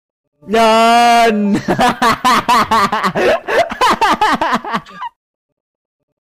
Category: Viral saying
tiếng meme